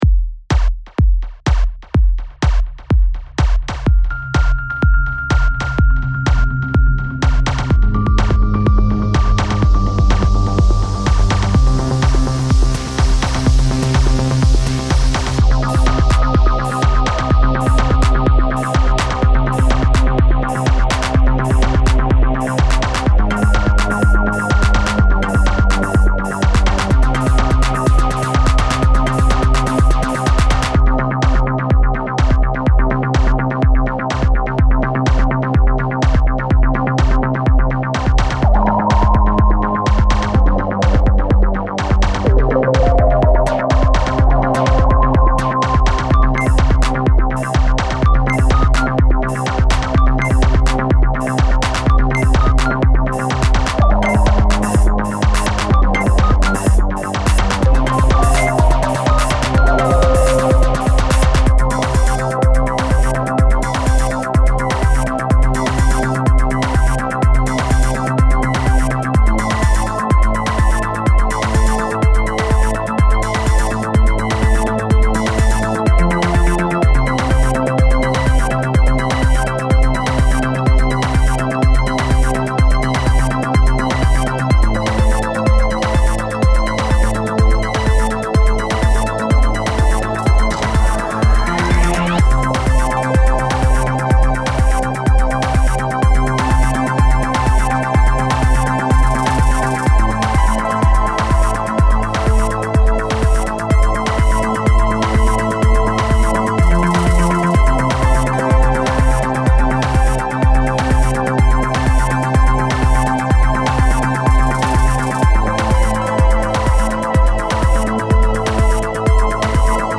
(индитроника)